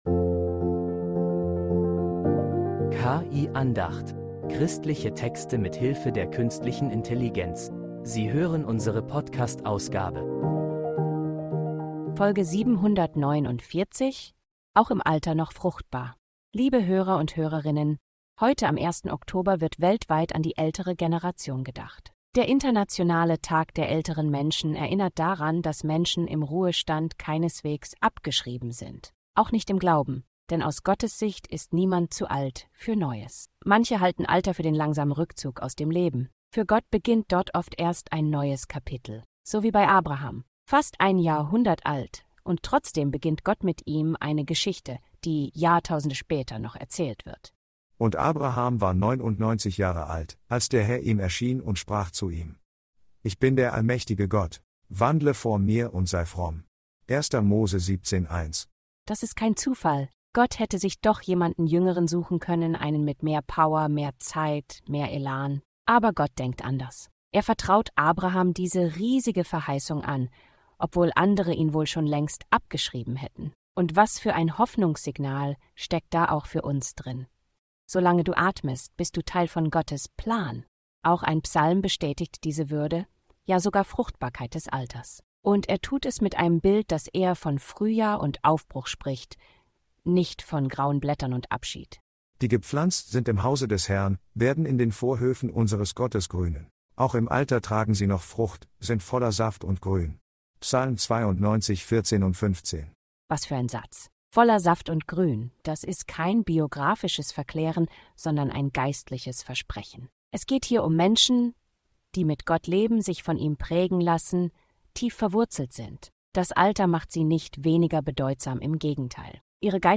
Eine Andacht über geistliche Reife